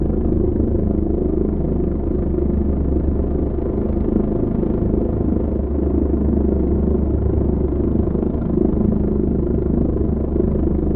H145_Vortex_In-left.wav